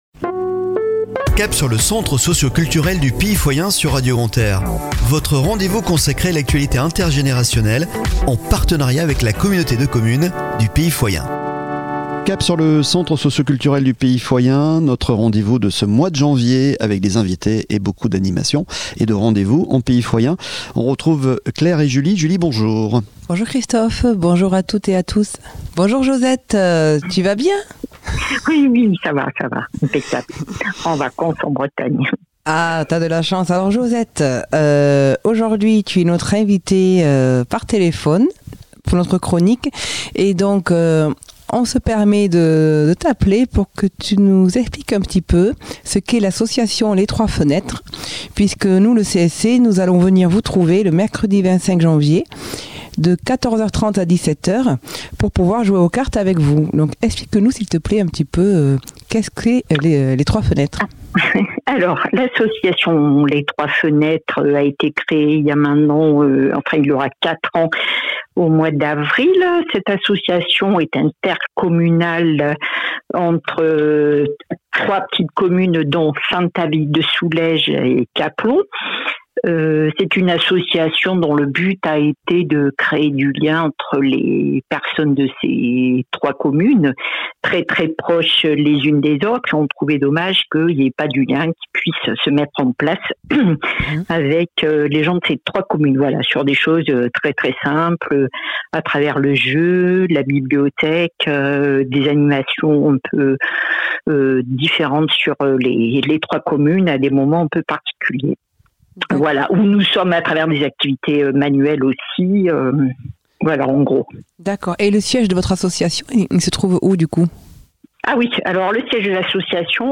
Chronique de la semaine du 23 au 29 Janvier 2023 !